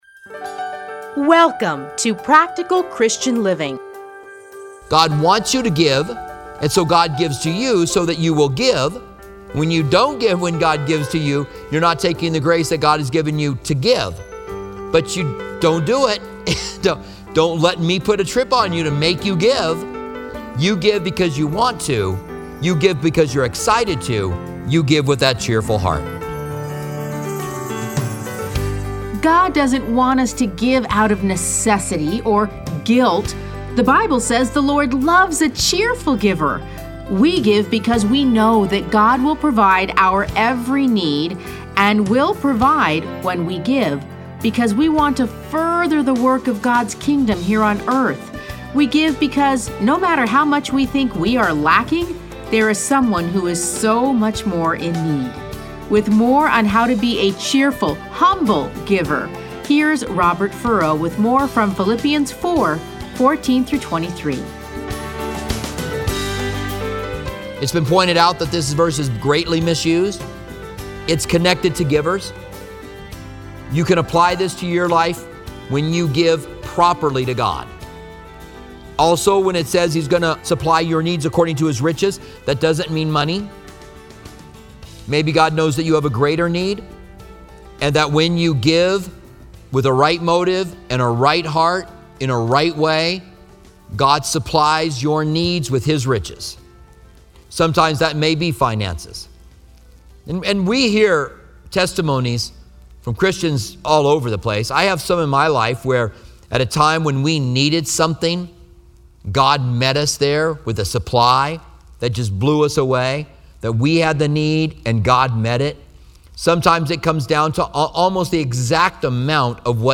Listen to a teaching from Philippians 4:14-23.